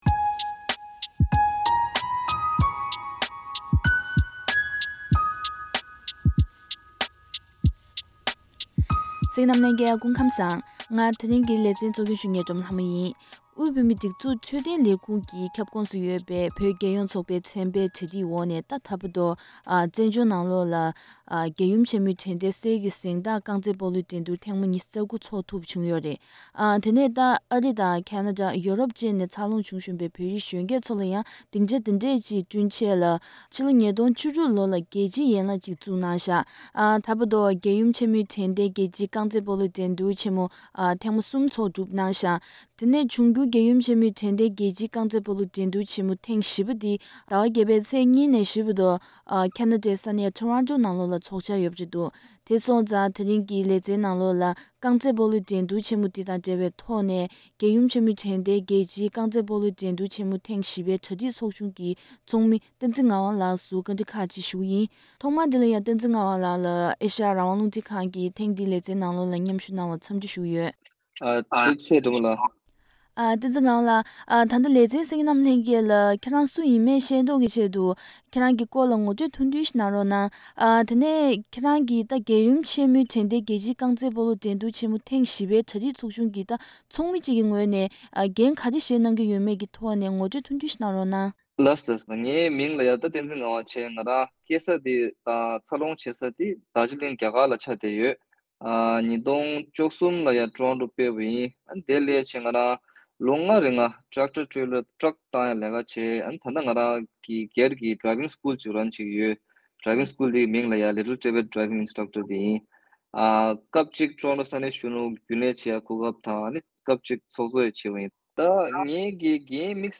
དྲི་བ་ཁག་ལ་ལན་འདེབས་གནང་བ་ཞིག་གསན་གནང་གི་རེད།